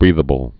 (brēthə-bəl)